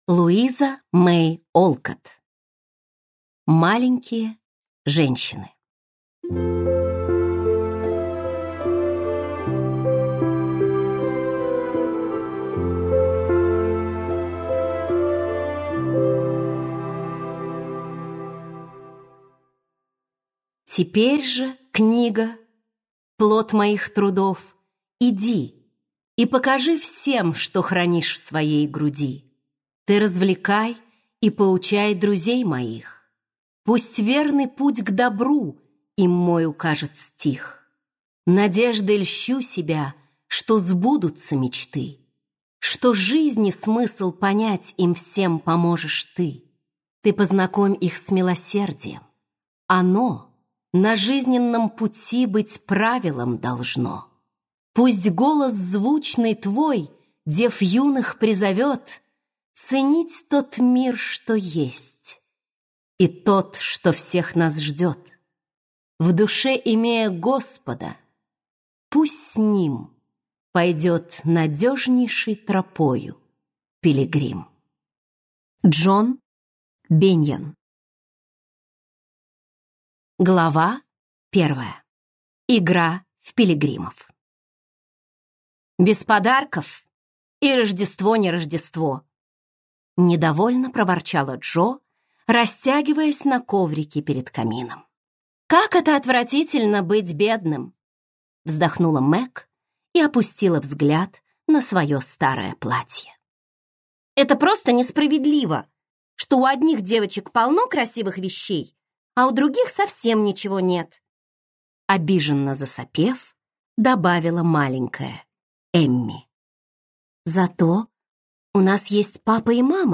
Аудиокнига Маленькие женщины - купить, скачать и слушать онлайн | КнигоПоиск